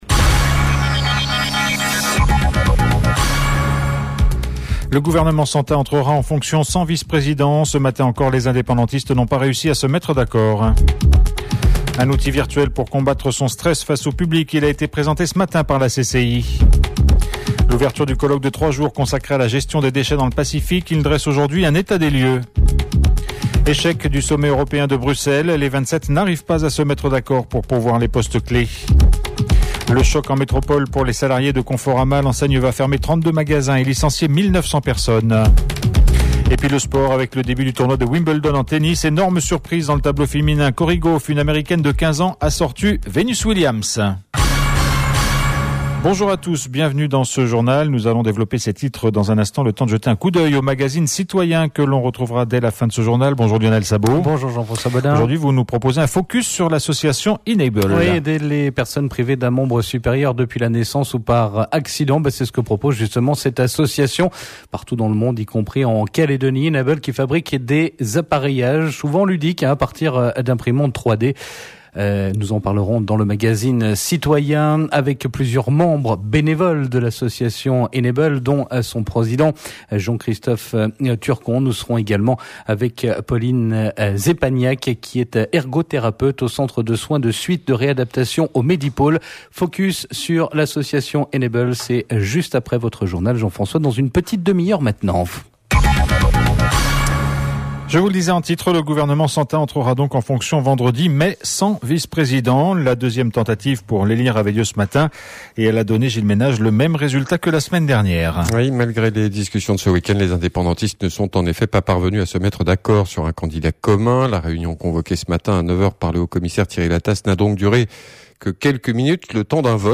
JOURNAL MARDI 02/07/19 (MIDI)